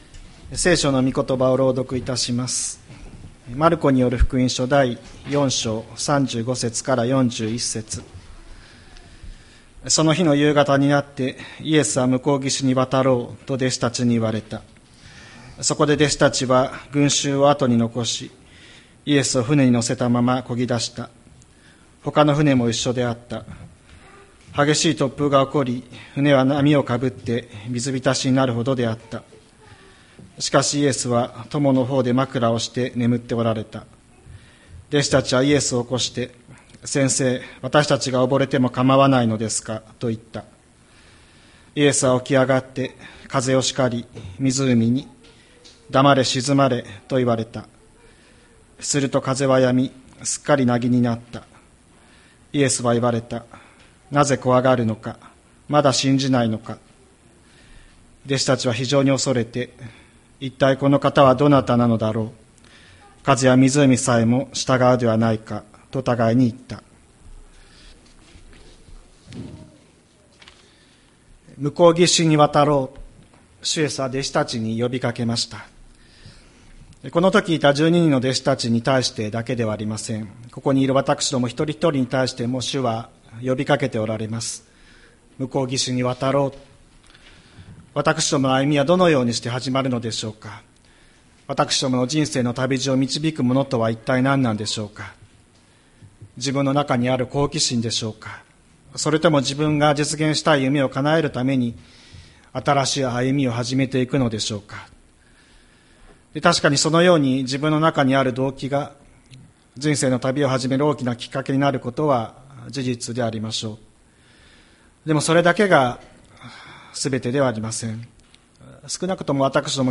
千里山教会 2025年05月18日の礼拝メッセージ。